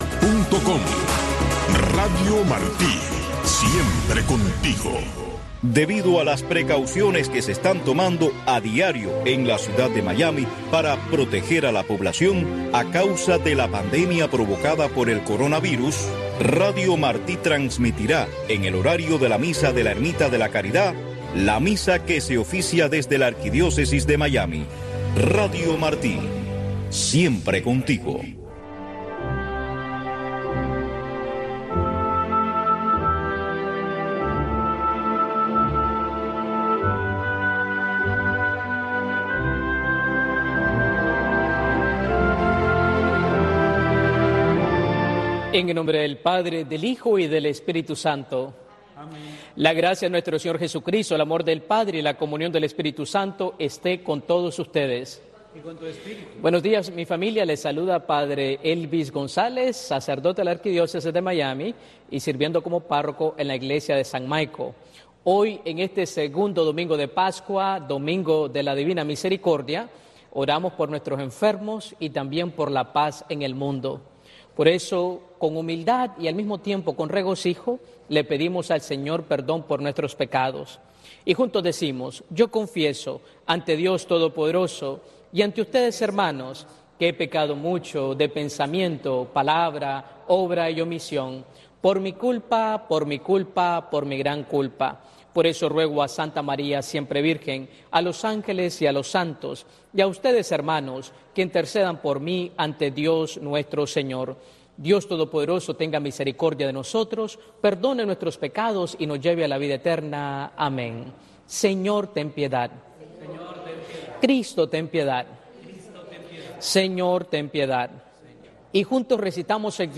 La Santa Misa